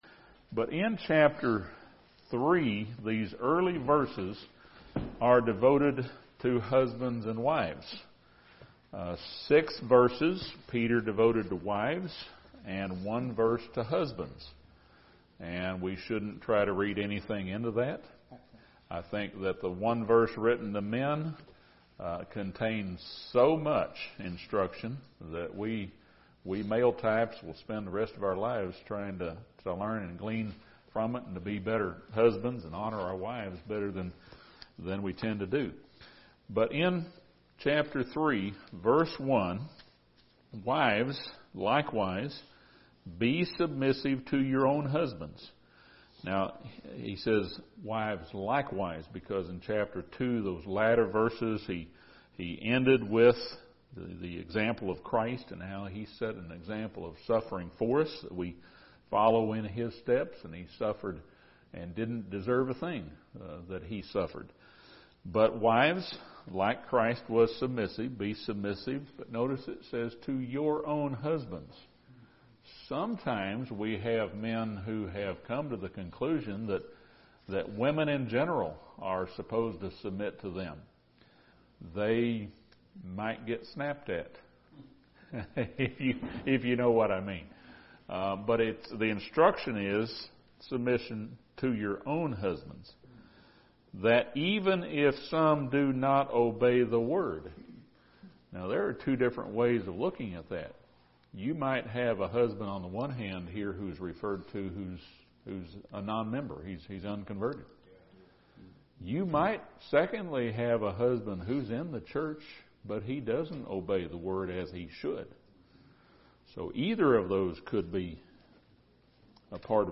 This Bible study surveys chapters 3 and 4 of 1 Peter.